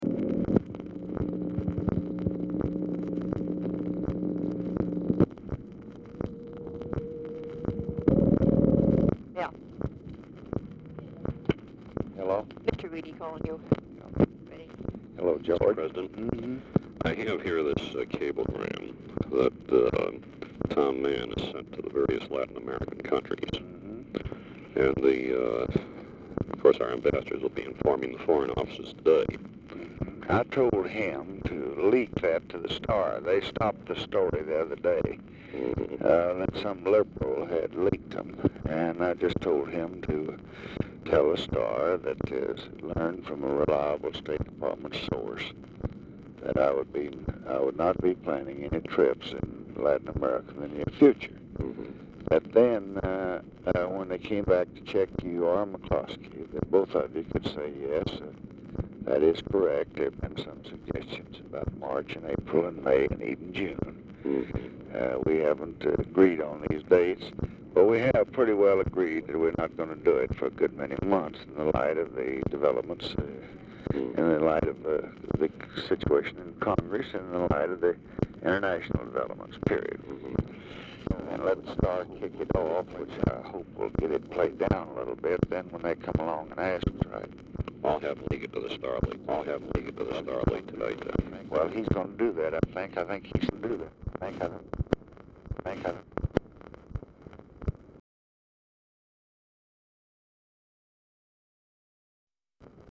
Telephone conversation
Format Dictation belt
Location Of Speaker 1 Mansion, White House, Washington, DC